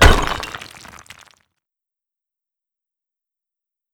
Break Stone.wav